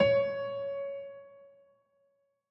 files AfterStory/Doki Doki Literature Club/game/mod_assets/sounds/piano_keys
C5sh.ogg